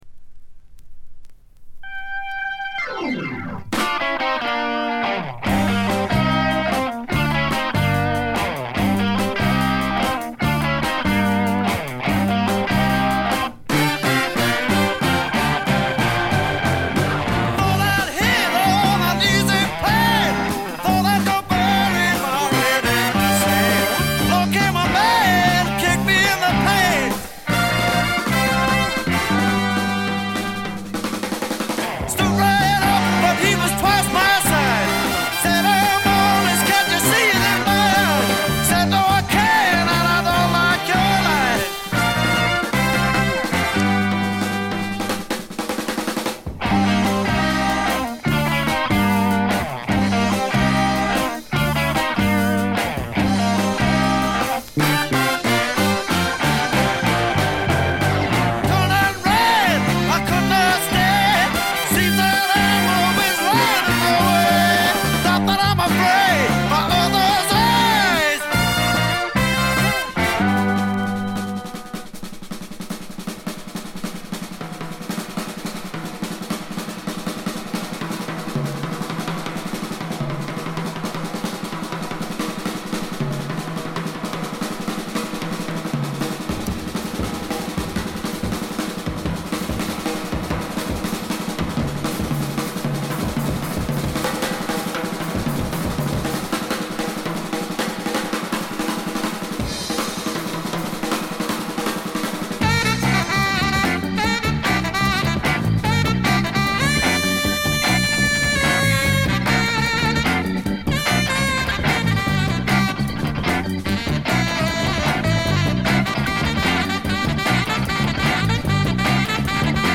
静音部でのわずかなノイズ感程度。
試聴曲は現品からの取り込み音源です。